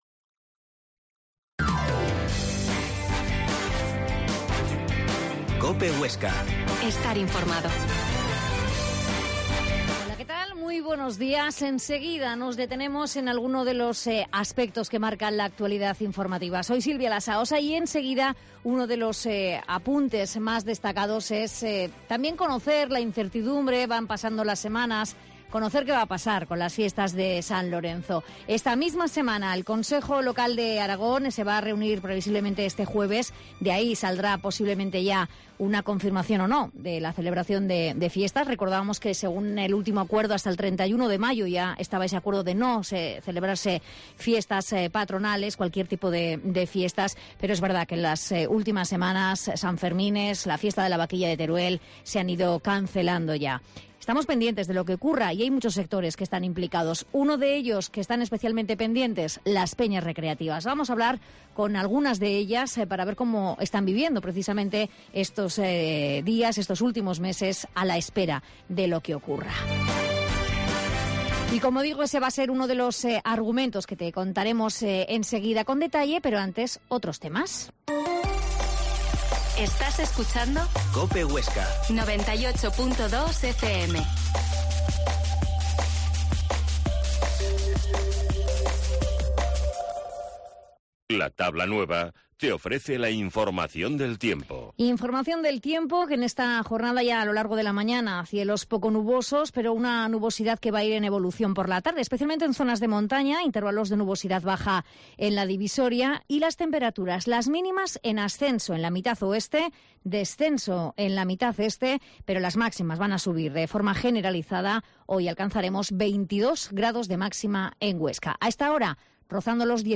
Herrera en Cope Huesca 12,50h. Entrevista a representantes de las Peñas
La Mañana en COPE Huesca - Informativo local Herrera en Cope Huesca 12,50h.